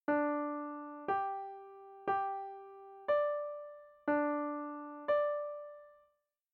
A perfect fourth followed by a perfect fifth, in either order, add up to an octave.